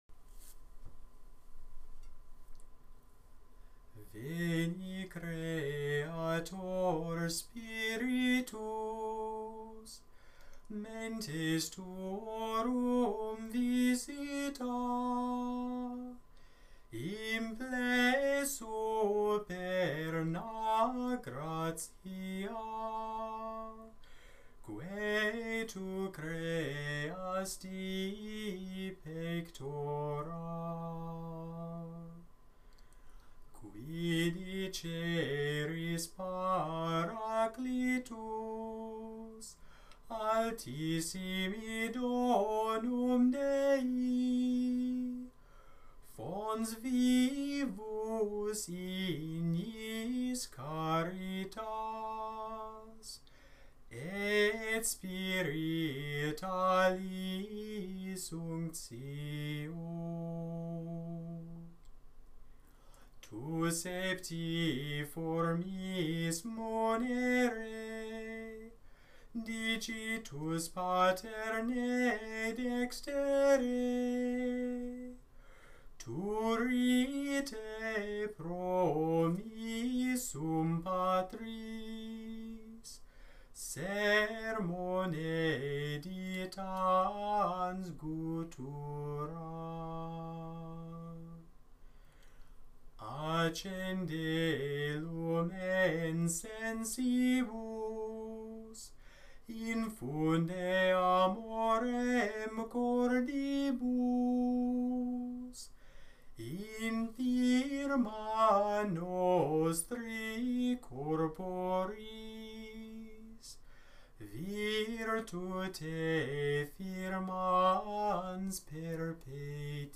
Gregorian, Catholic Chant Veni Creator Spiritus
Use: Mass